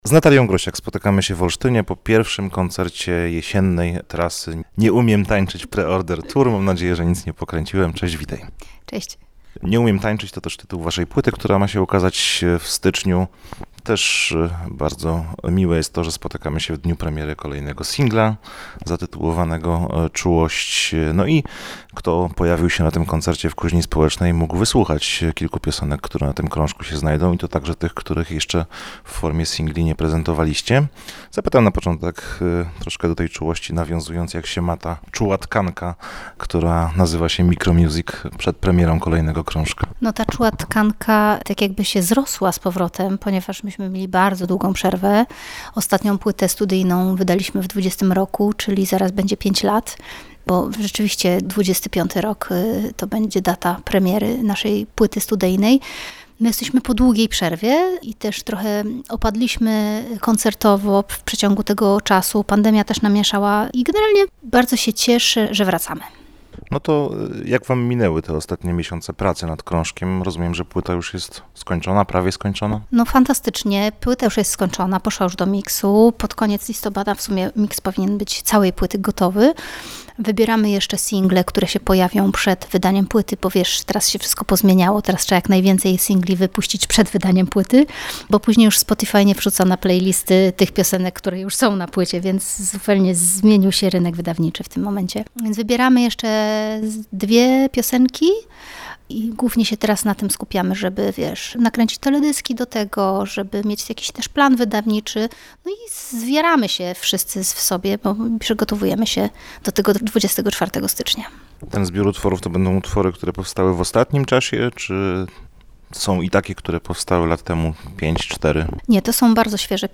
Czuła rozmowa z Natalią Grosiak